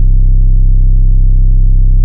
motorsport sub.wav